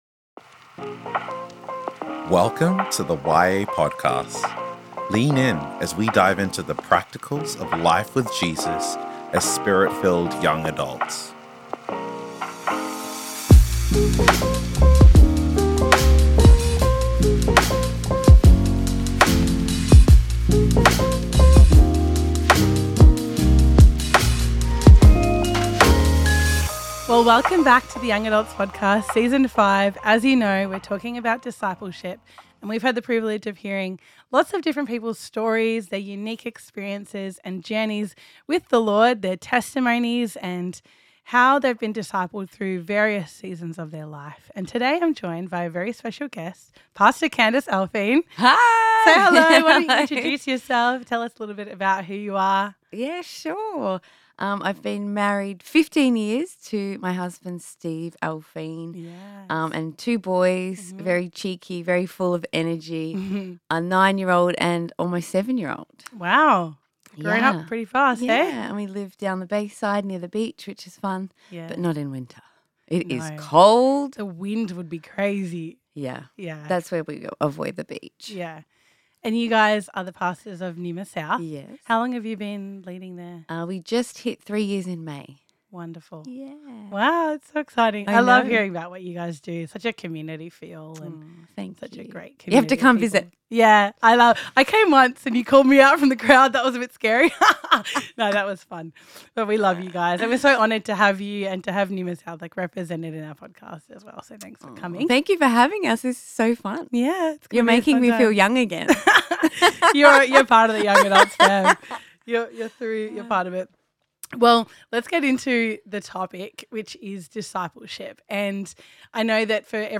Discipleship Conversations: Consistency, Community and Change